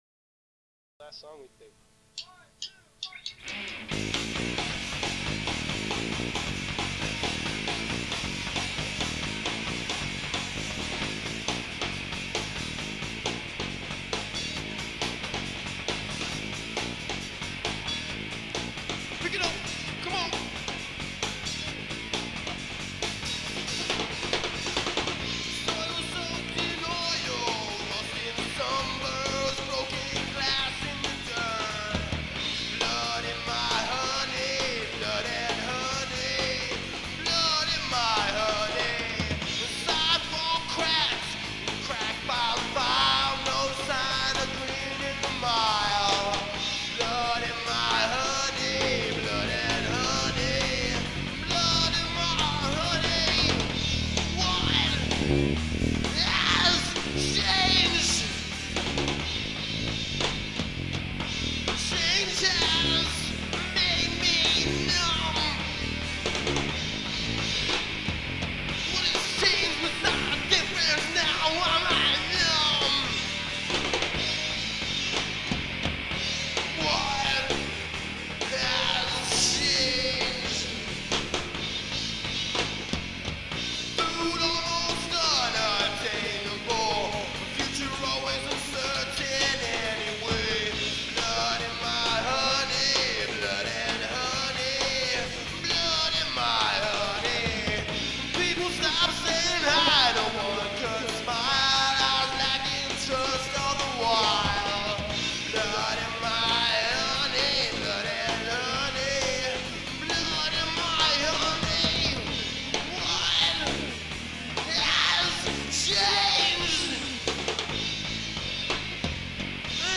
brutal, bone-rattling rock and roll
guitar
bass